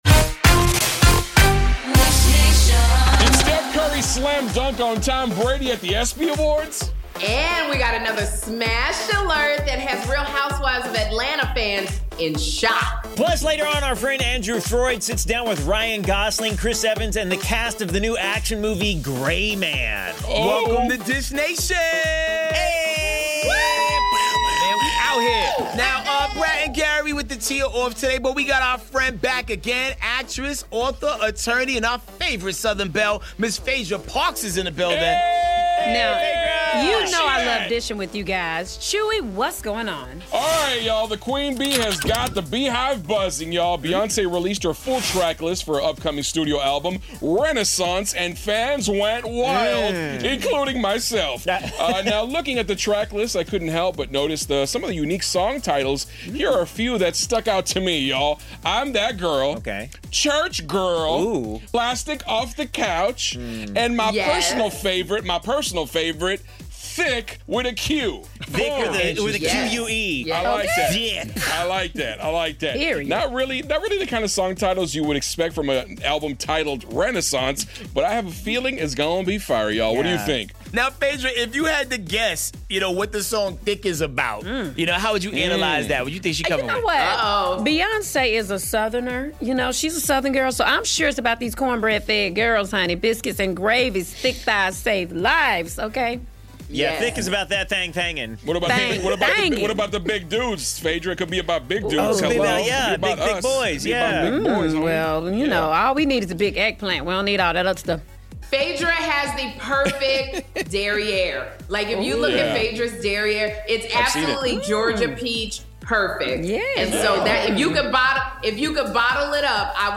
The BeyHive buzzes about Beyonce's album tracklist, Dwayne 'The Rock' Johnson and Kevin Hart get slapped around, and is 'RHOA' star Sheree Whitfield dating 'Love & Marriage: Huntsville' star Martell Holt? 'RHOA' veteran Phaedra Parks co-hosts with us, so tune in for her tea!